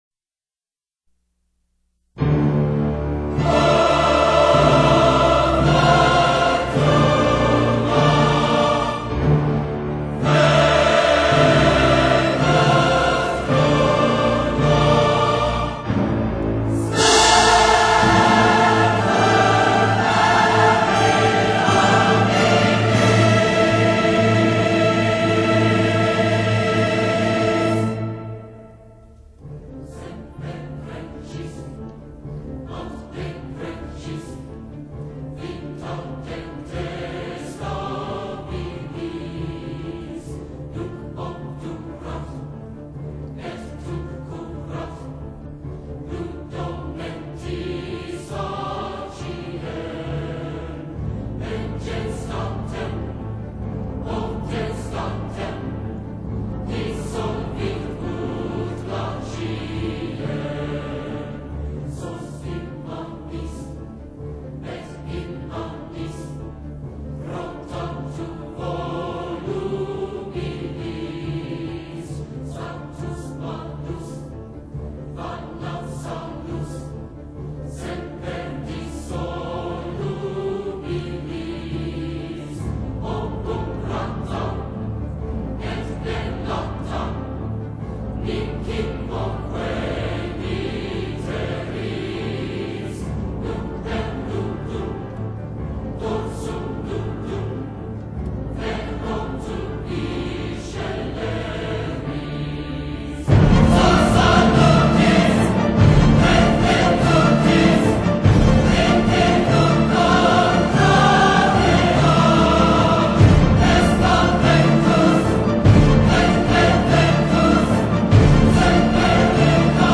【所属类别】音乐 古典